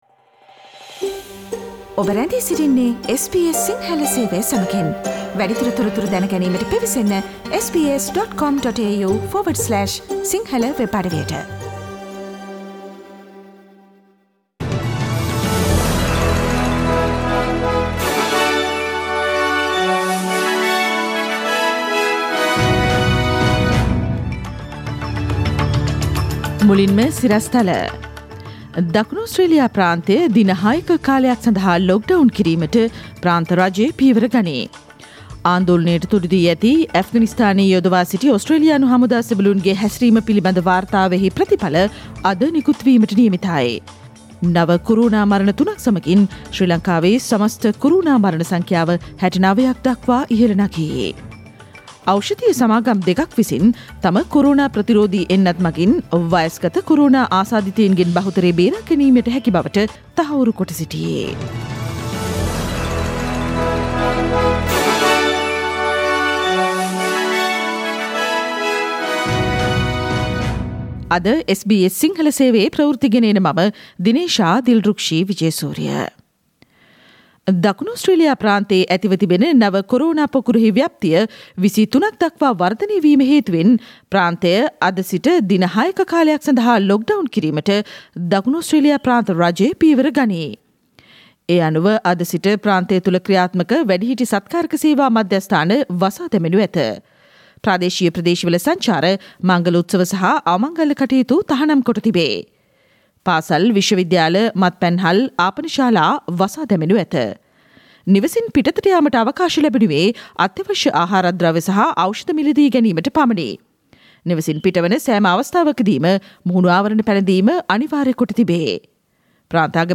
Today’s news bulletin of SBS Sinhala radio – Thursday 19 November 2020
Daily News bulletin of SBS Sinhala Service: Thursday 19 November 2020.